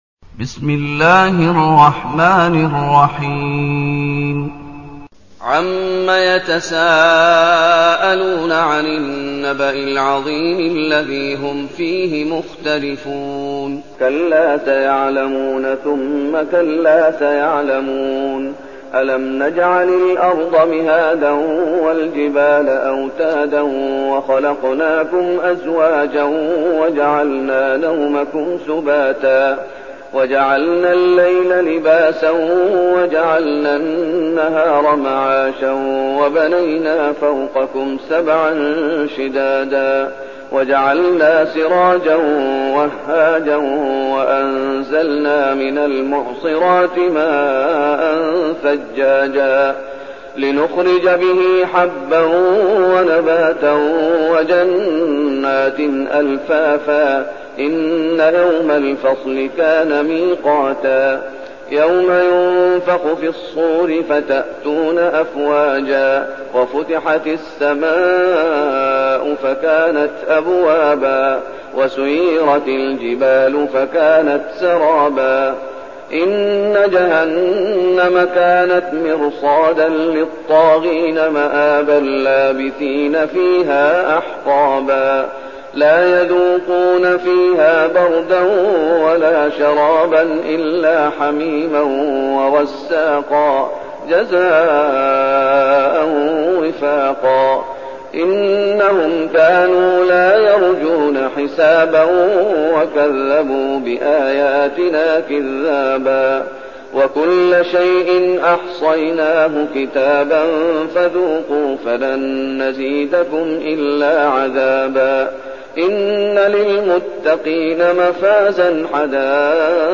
المكان: المسجد النبوي الشيخ: فضيلة الشيخ محمد أيوب فضيلة الشيخ محمد أيوب النبأ